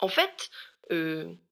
VO_ALL_Interjection_11.ogg